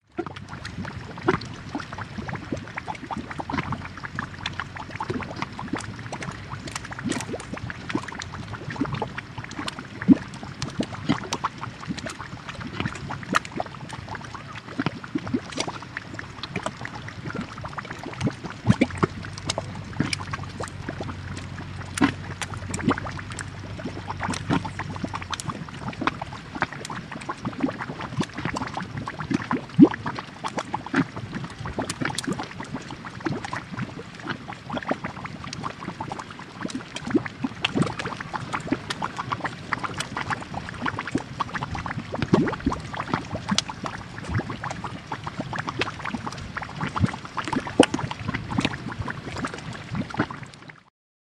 Звуки извержения вулкана
• Качество: высокое
Шум кипящей вулканической лавы